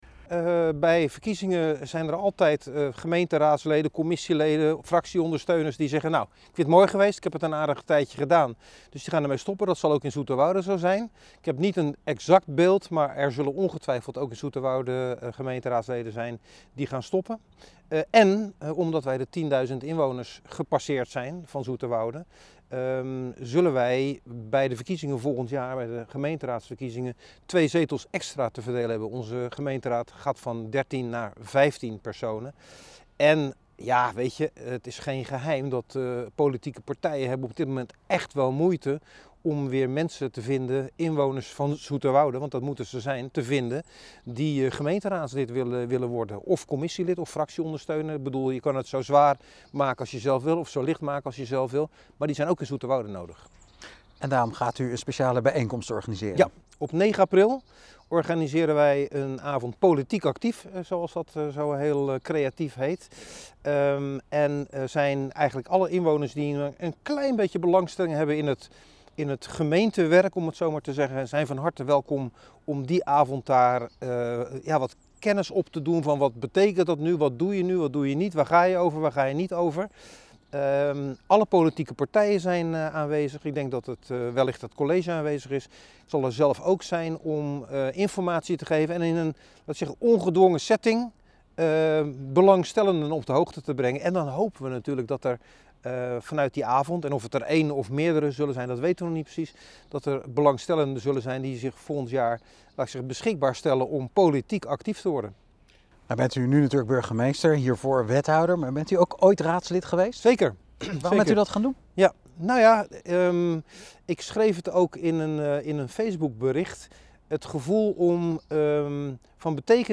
Burgmeester Fred van Trigt over de avond ‘Politiek actief’.